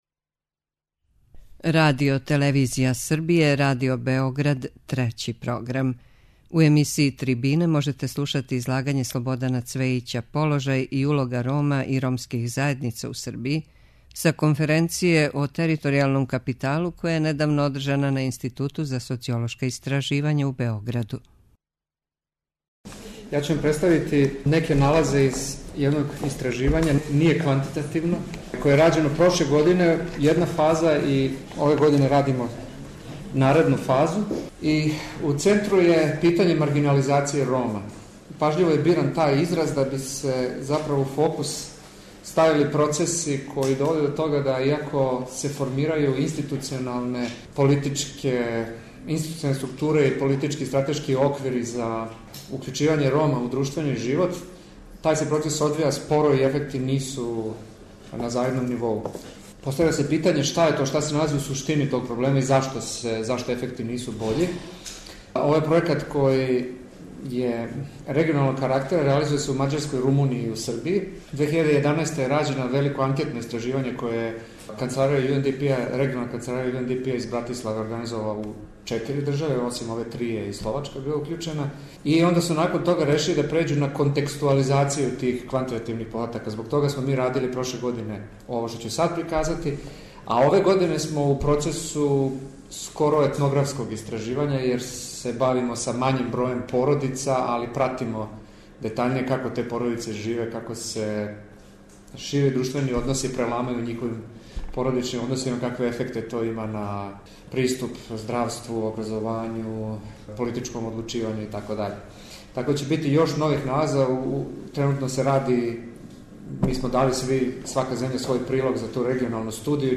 са конференције о територијалном капиталу која је недавно одржана на Институту за социолошка истраживања у Београду.
преузми : 9.69 MB Трибине и Научни скупови Autor: Редакција Преносимо излагања са научних конференција и трибина.